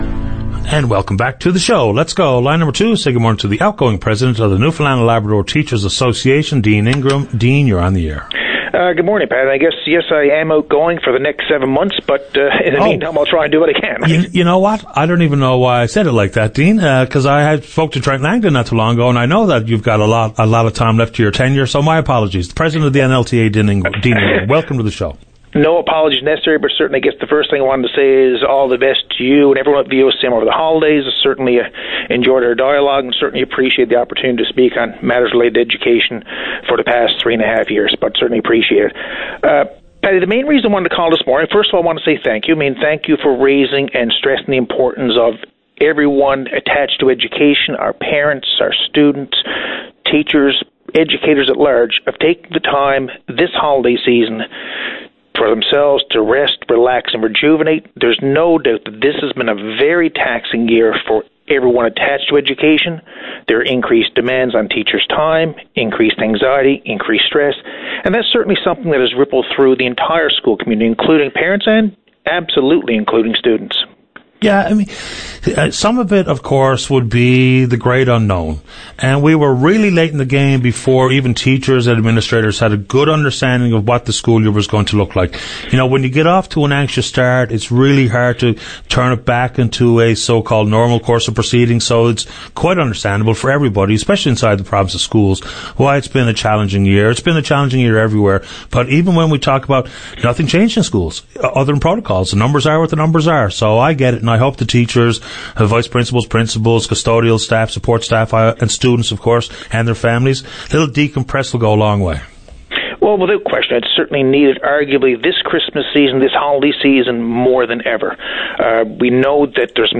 Media Interview - VOCM Open Line Dec 21, 2020